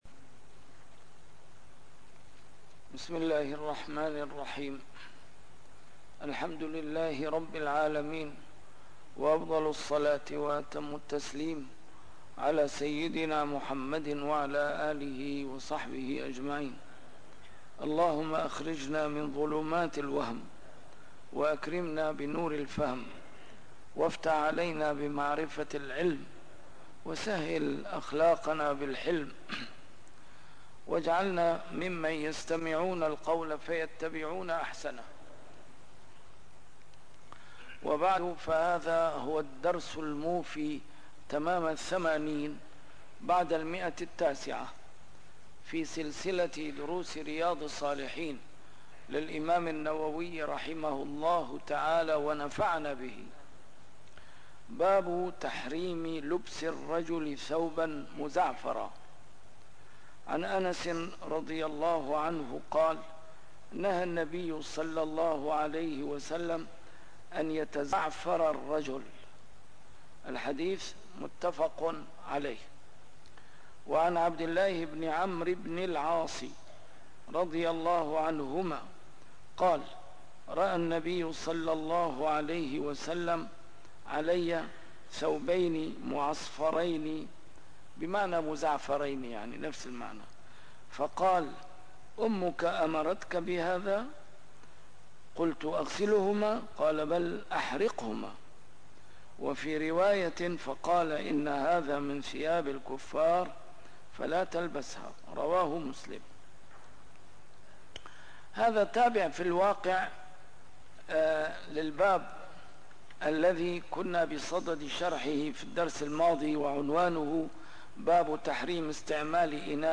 A MARTYR SCHOLAR: IMAM MUHAMMAD SAEED RAMADAN AL-BOUTI - الدروس العلمية - شرح كتاب رياض الصالحين - 980- شرح رياض الصالحين: تحريم لبس الرجل ثوباً مزعفراً - النهي عن صمت يوم إلى الليل - تحريم انتساب الإنسان إلى غير أبيه